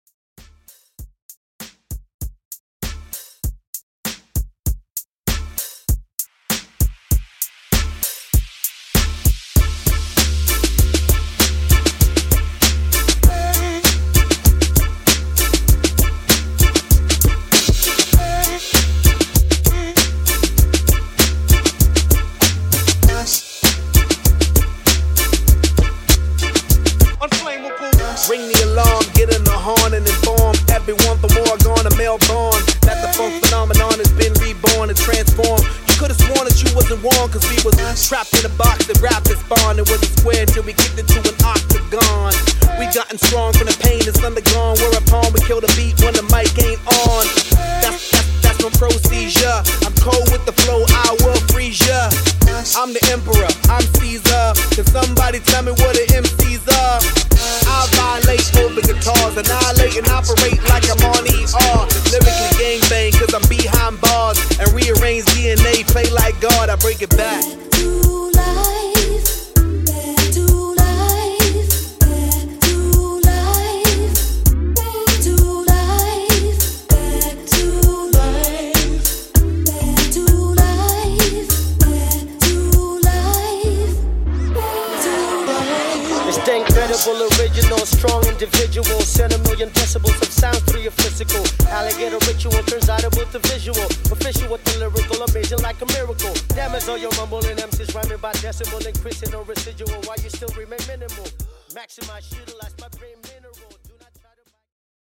HipHop Redrum)Date Added